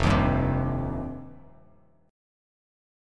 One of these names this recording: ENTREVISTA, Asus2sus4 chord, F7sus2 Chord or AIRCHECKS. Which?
F7sus2 Chord